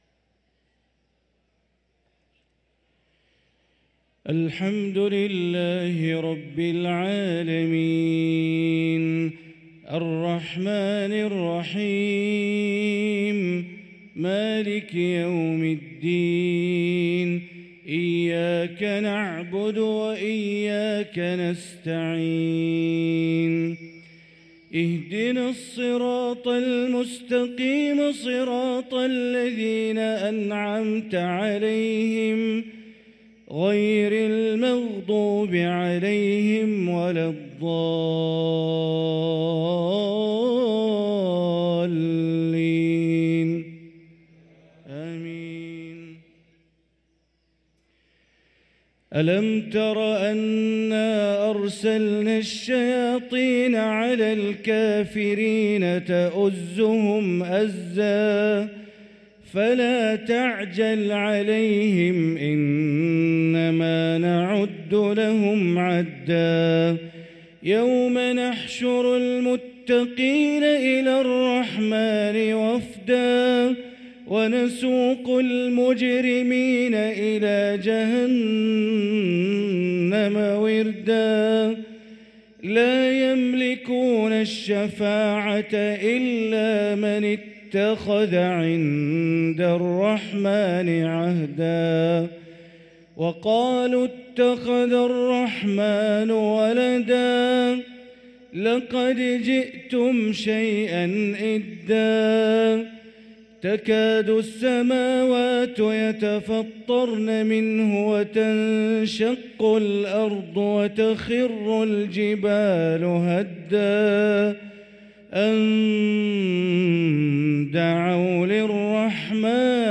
صلاة المغرب للقارئ بندر بليلة 10 رجب 1445 هـ